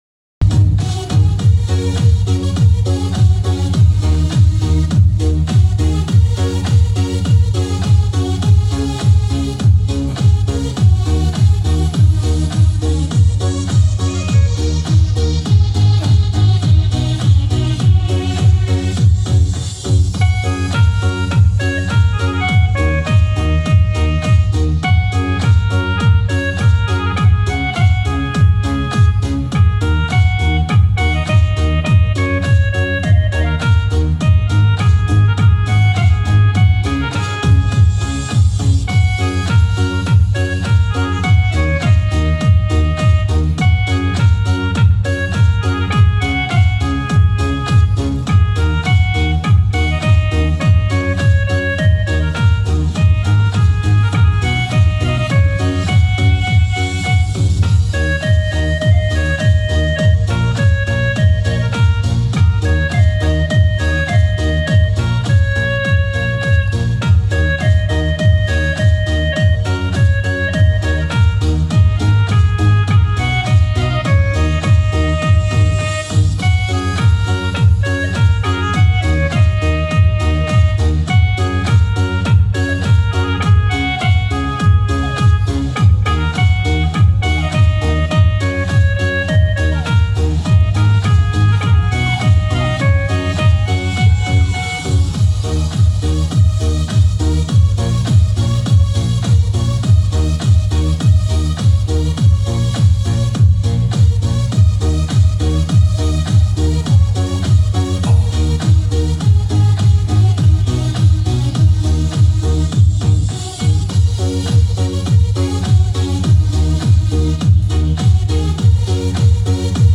DJ版的，咱们同乐一下，哈哈。